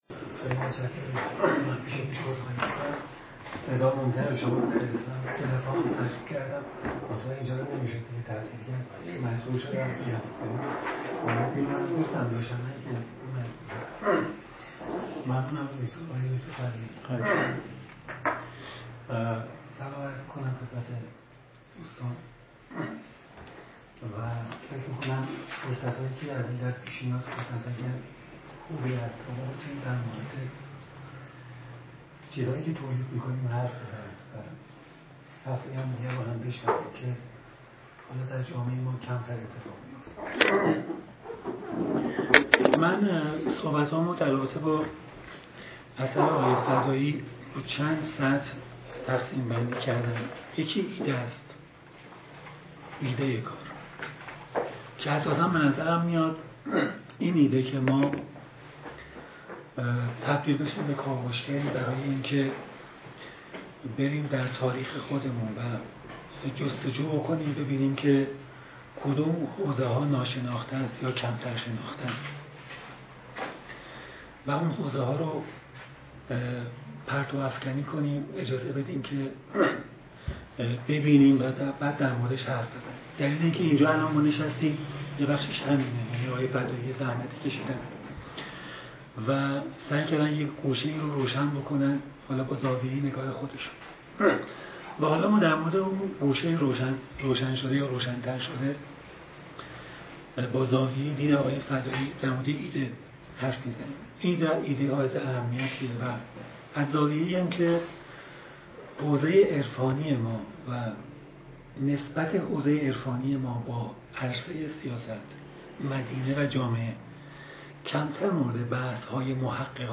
سخنرانی
در انجمن علوم سیاسی برگزار شد